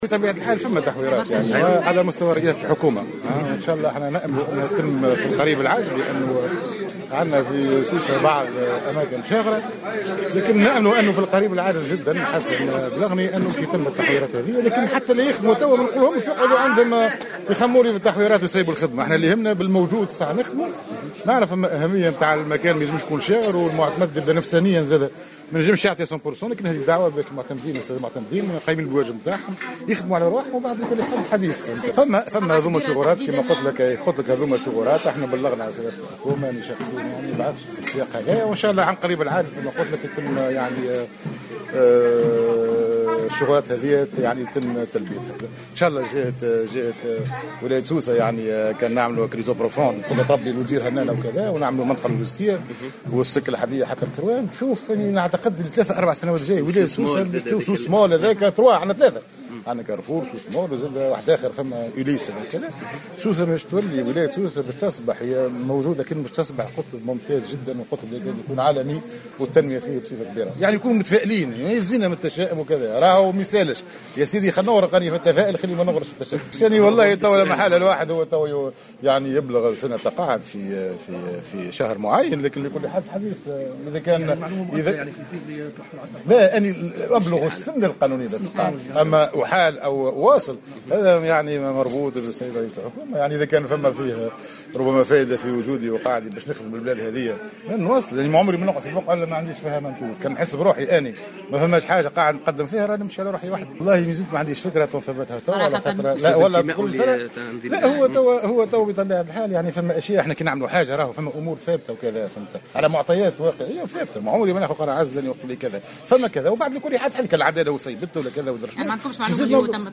وأشار بديرة، في تصريح للجوهرة أف أم، على هامش زيارته إلى النفيضة بمناسبة إحياء الذكرى الستين لتأسيس بلدية المكان، إلى وجود عديد المشاريع المبرمجة في الجهة، من بينها إحداث منطقة لوجستية ومد خط السكة الحديدة ليصل إلى ولاية القيروان.